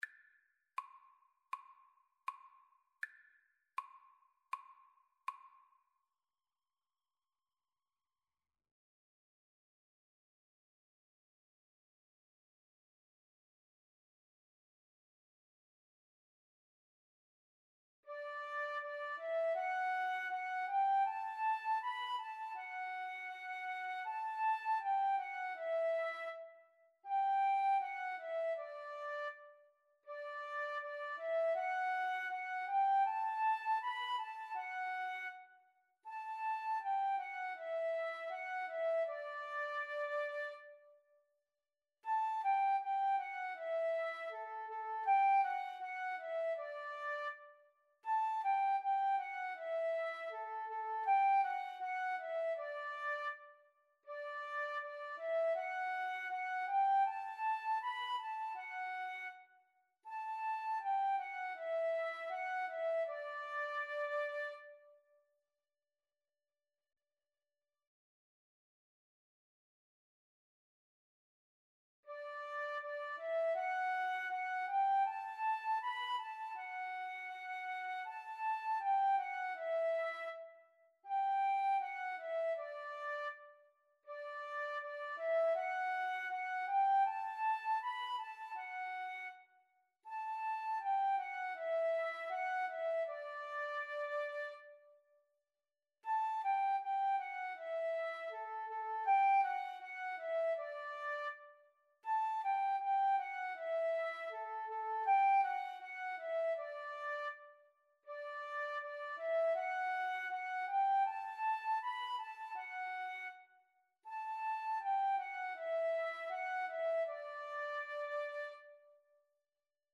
FluteGuitar
4/4 (View more 4/4 Music)
Andante
Guitar-Flute Duet  (View more Easy Guitar-Flute Duet Music)